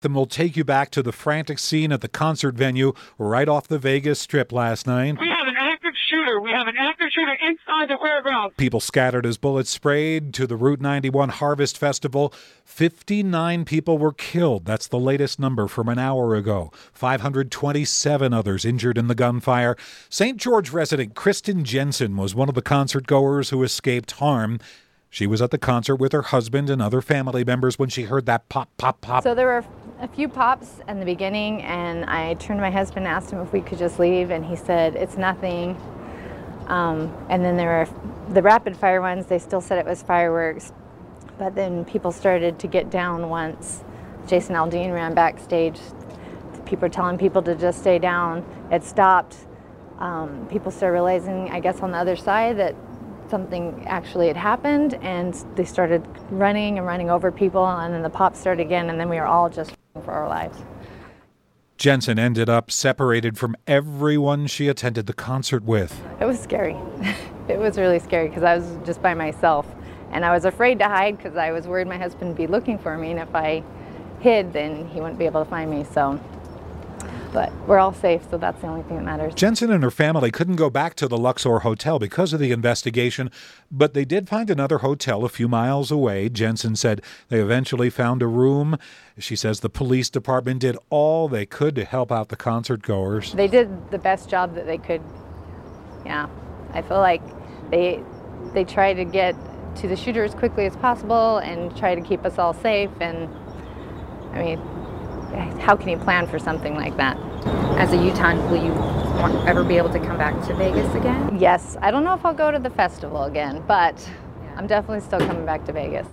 Utahn recounts mass shooting in Las Vegas